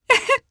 Demia-Vox-Laugh_jp_b.wav